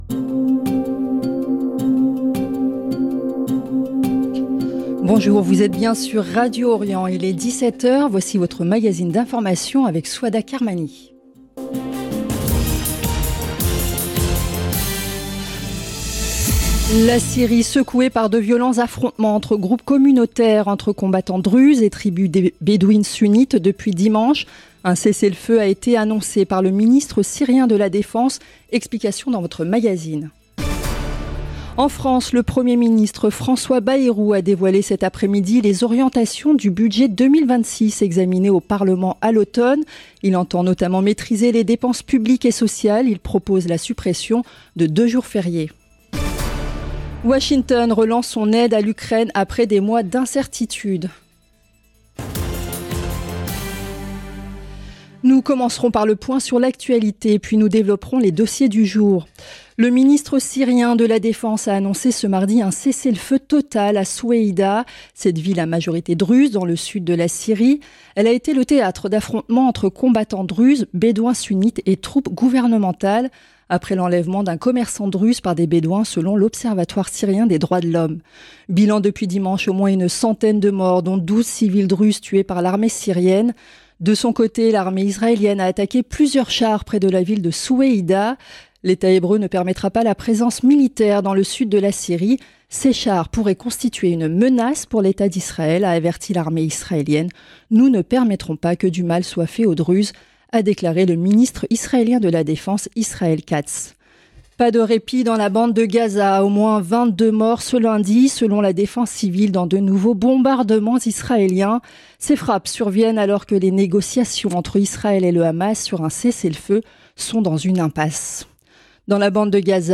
Magazine d'information de 17H du 15 juillet 2025